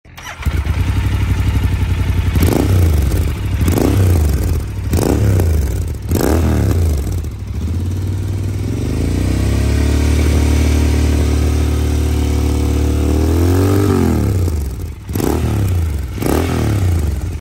Growler Exhaust with dB Killer
• Sporty note with deep bass
In addition to all the pros, Dug Dug Growler Exhaust consists of dB killer that keeps it the sound as good as stock once installed back after the adrenaline rush.
Growler-with-dB.mp3